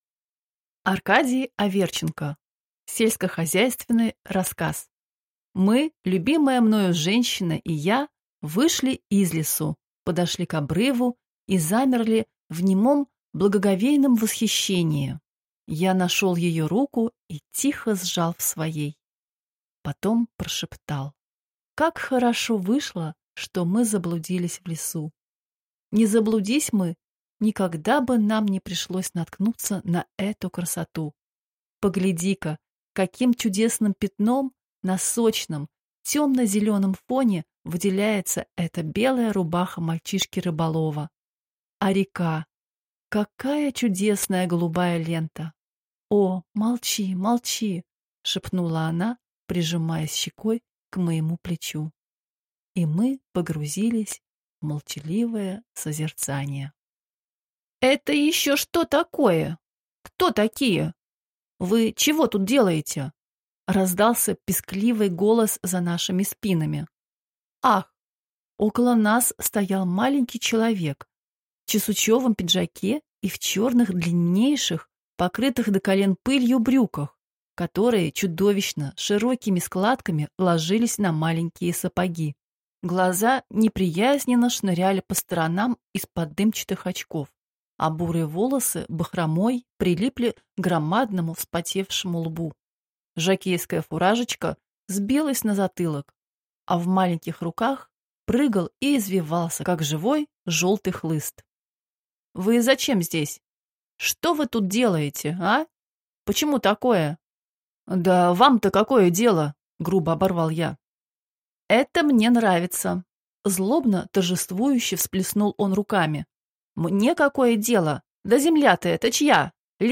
Аудиокнига Сельскохозяйственный рассказ | Библиотека аудиокниг